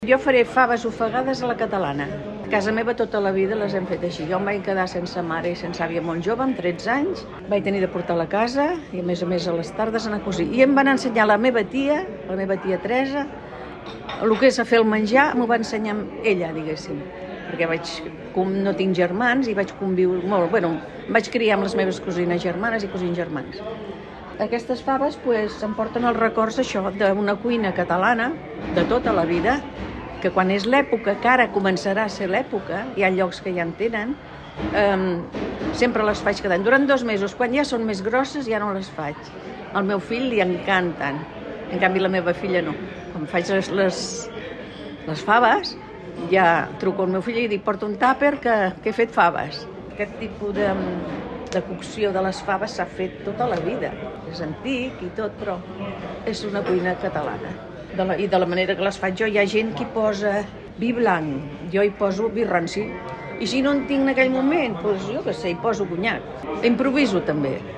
cuinera-senyora.mp3